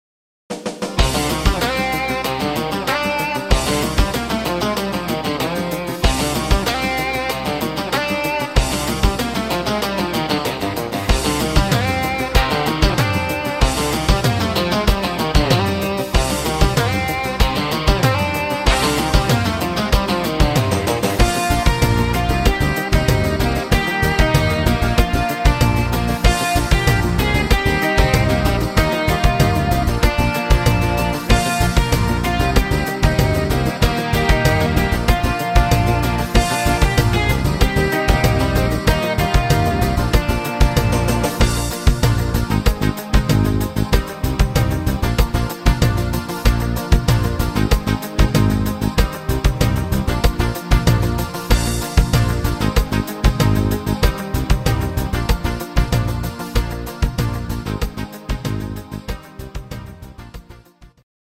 fetziger Rock Oldie